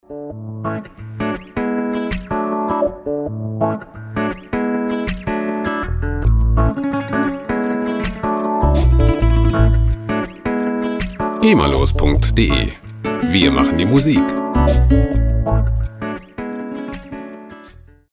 lizenzfreie Latin Musik
Musikstil: Latin Pop
Tempo: 162 bpm